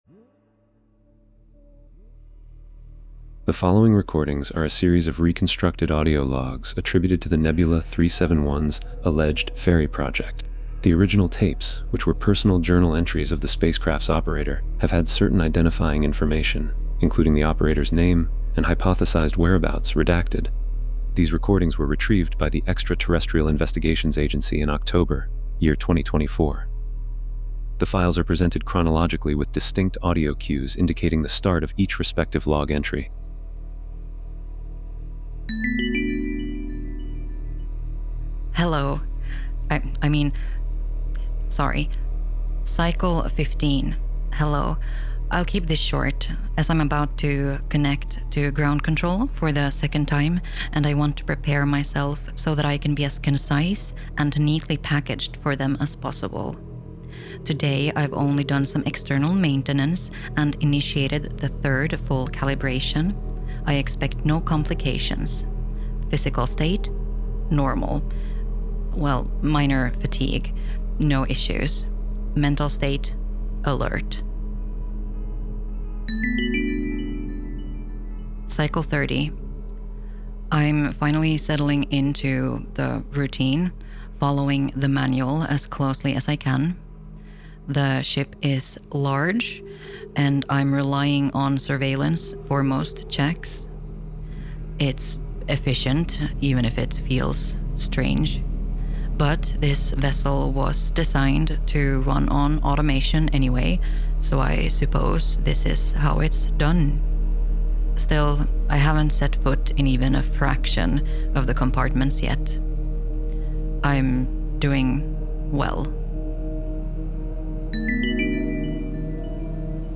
Audio based installation